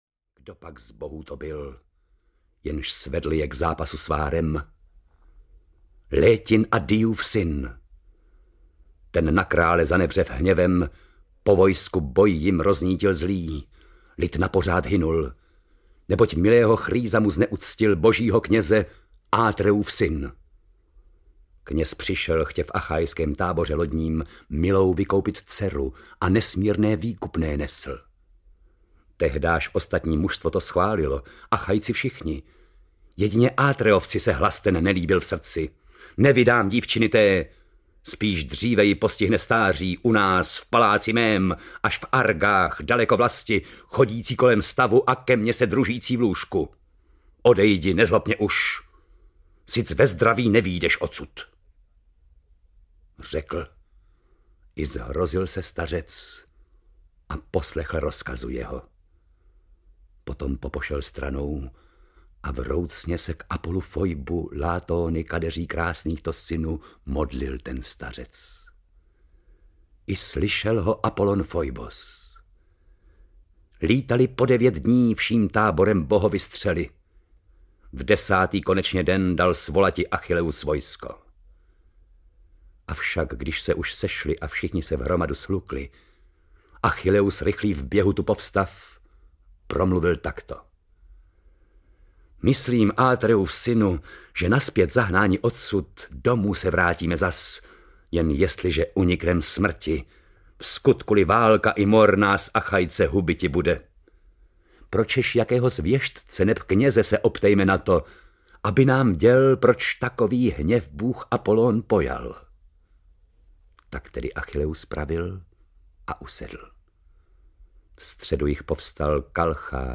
Myšlenky antických klasiků audiokniha
Ukázka z knihy